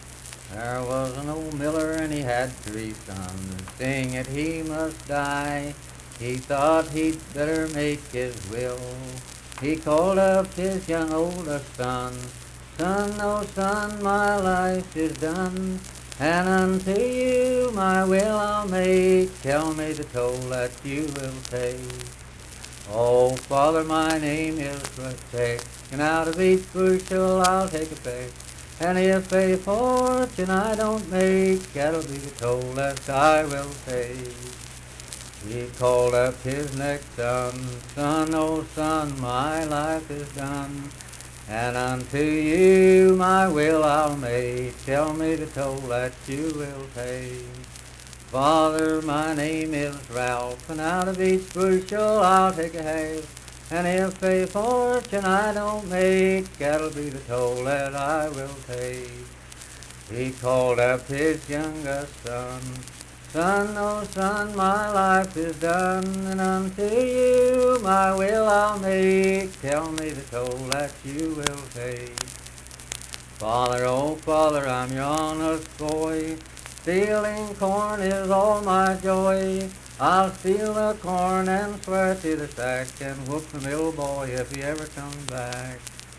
Unaccompanied vocal music performance
Verse-refrain 3(8).
Performed in Kliny, Pendleton County, WV.
Voice (sung)